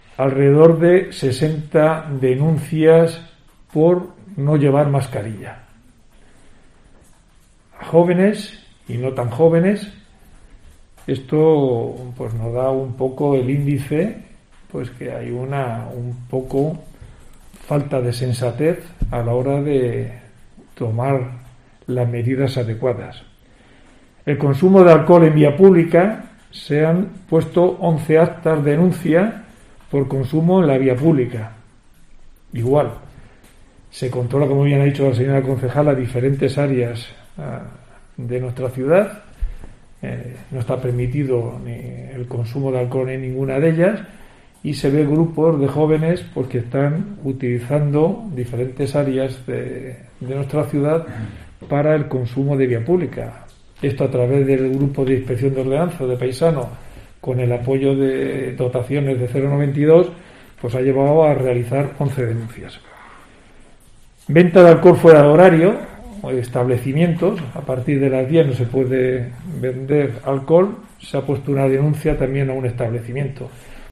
Declaraciones del Intendente de policía local